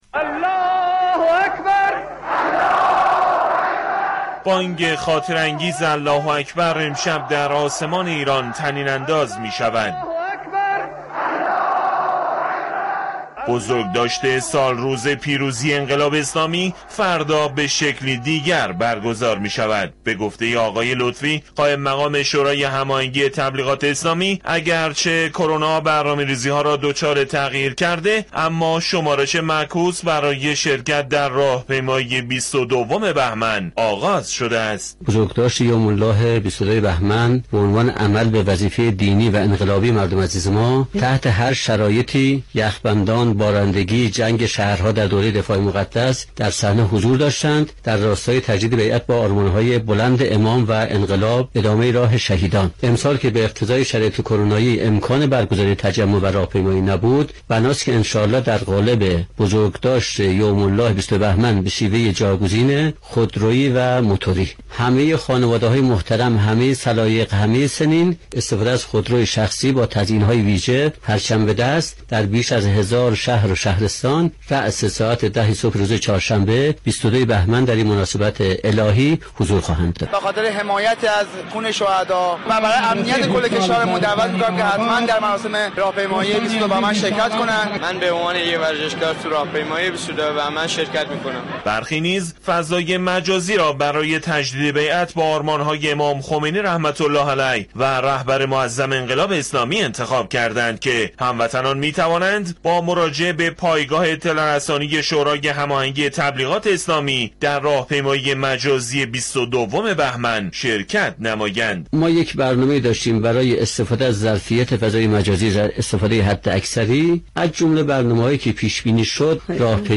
گزارشی را در این زمینه بشنوید: دریافت فایل کلمات کلیدی: #22 بهمن ماه